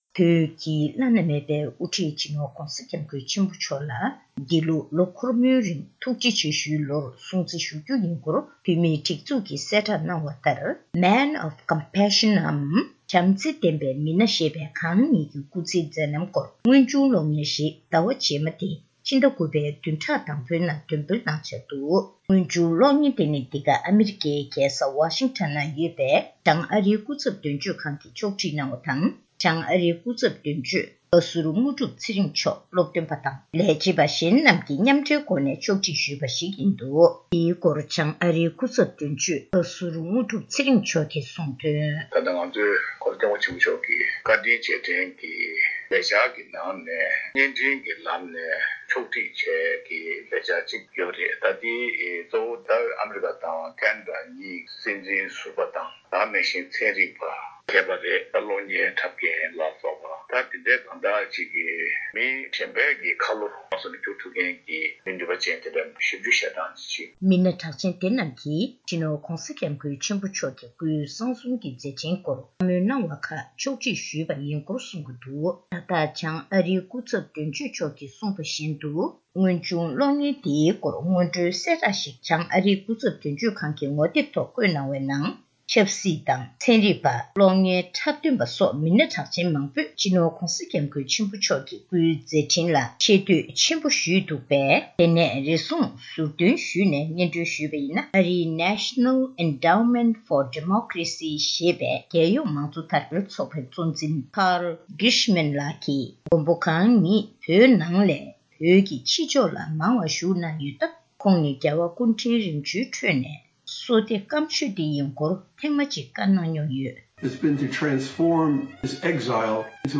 གནས་ཚུལ་ཕྱོགས་བསྒྲིགས་དང་སྙན་སྒྲོན་ཞུས་པར་གསན་རོགས་ཞུ།།